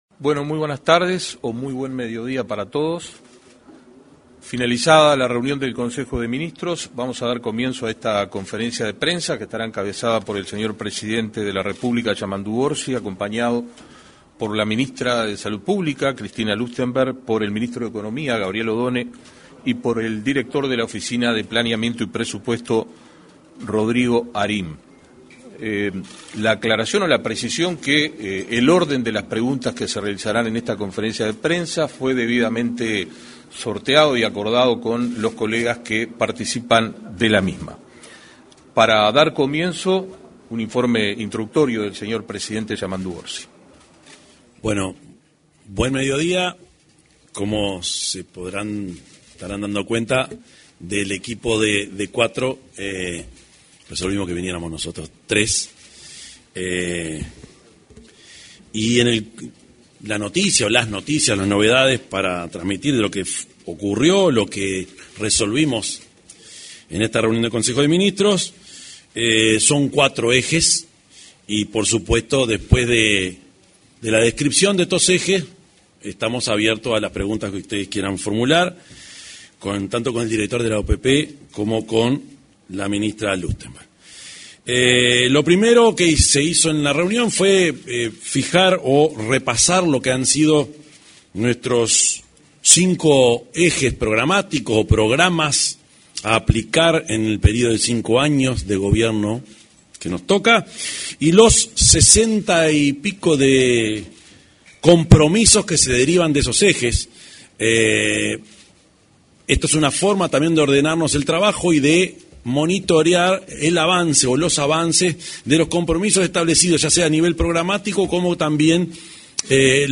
Conferencia de prensa - Consejo de Ministros
Conferencia de prensa - Consejo de Ministros 25/03/2025 Compartir Facebook X Copiar enlace WhatsApp LinkedIn Este martes 25 se realizó una conferencia de prensa, luego de finalizado el Consejo de Ministros. En la oportunidad se expresó el presidente de la República, profesor Yamandú Orsi; la ministra de Salud Pública, Cristina Lustemberg, y el director de la Oficina de Planeamiento y Presupuesto, Rodrigo Arim.